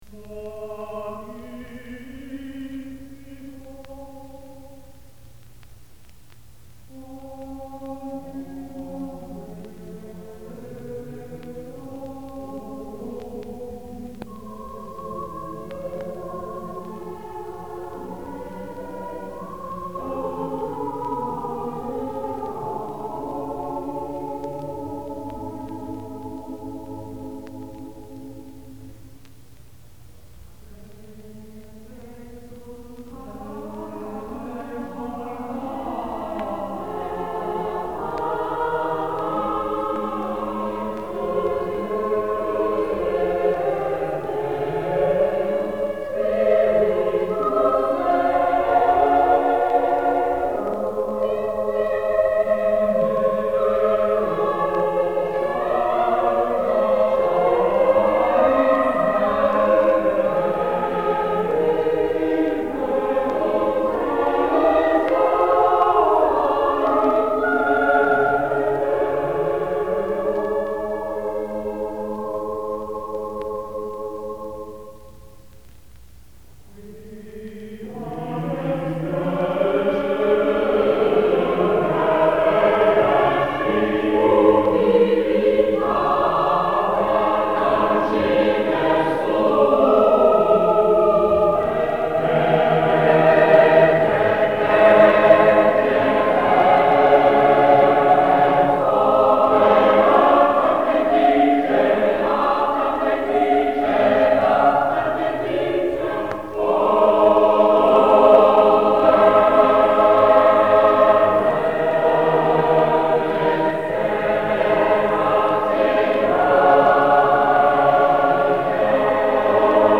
Renaissance
Victoria - Magnificat for 3 Choirs.mp3